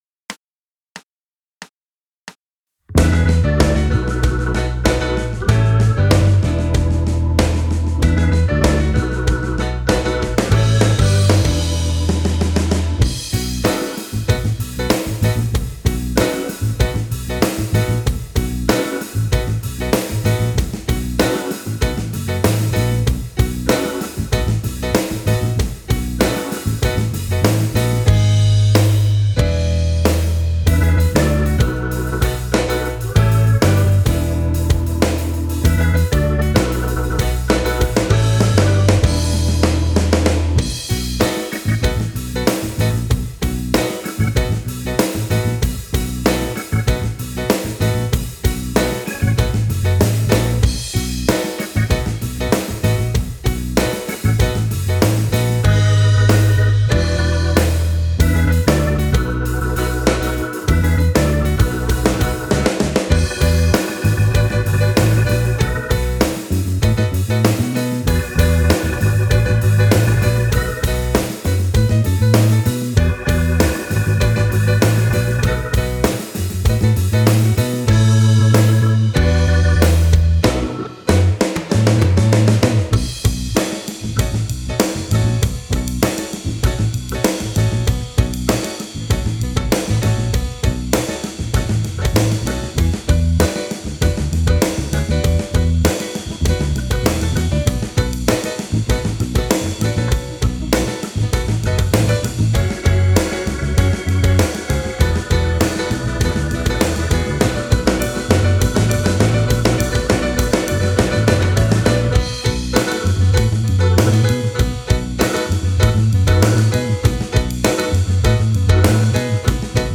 Performance Tracks
Bm